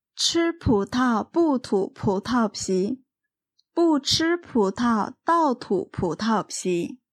発音のポイントは有気音の[ch]、[p]、[t]です。有気音は勢いよく息を吐くように発音します。